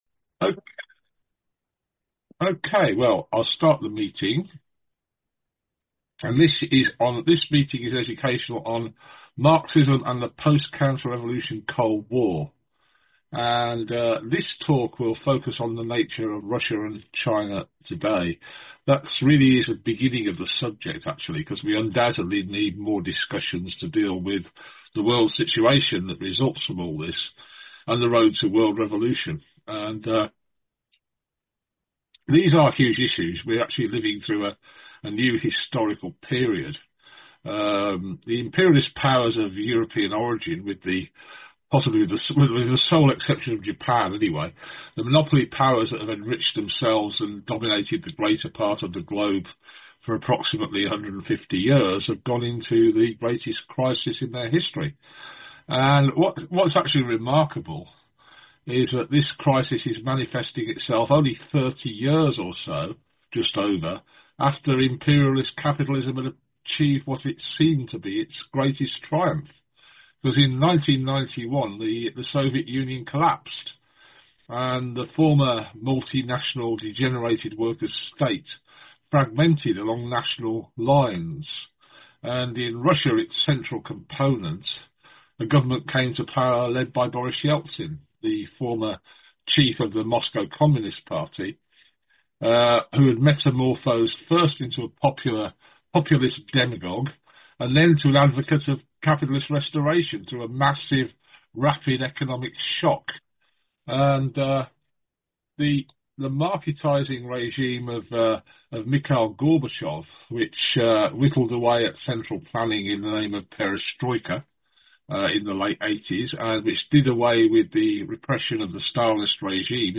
This presentation, by a Consistent Democrats speaker, and the discussion that followed it, is available as a podcast also.